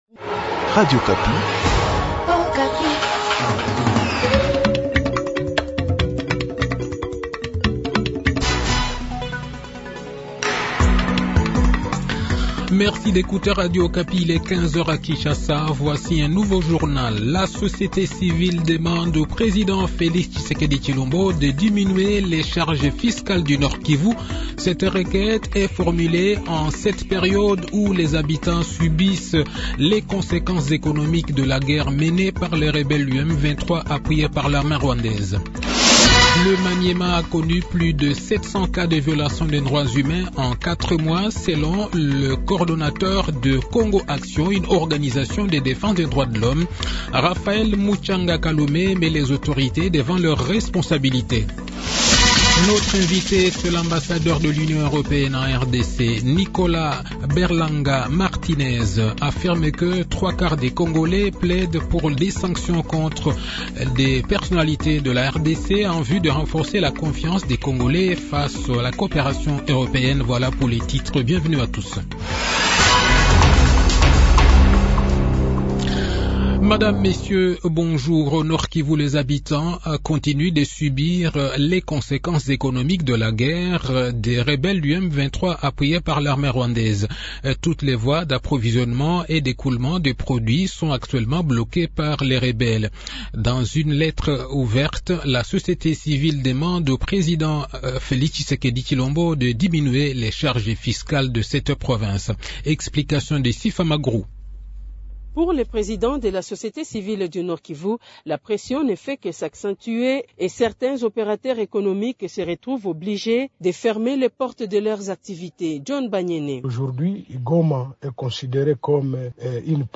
Le journal de 15 h